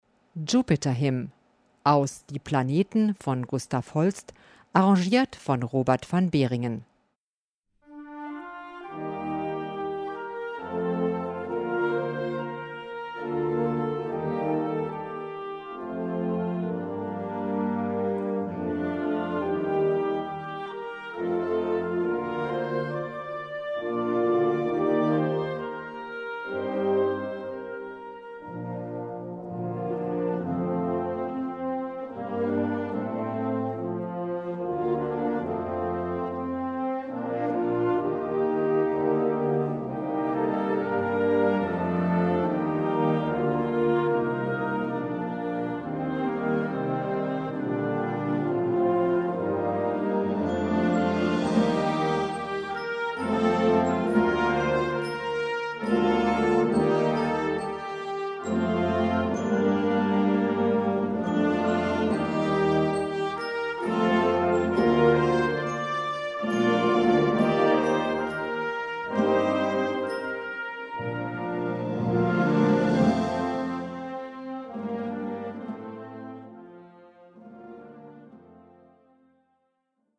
Besetzung: Blasorchester
prächtige choralartige Thema
für eine fünfstimmig variable Besetzung